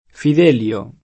vai all'elenco alfabetico delle voci ingrandisci il carattere 100% rimpicciolisci il carattere stampa invia tramite posta elettronica codividi su Facebook Fidelio [ fid $ l L o ; sp. fi D% l L o ; ted. fid % el L o ] pers. m.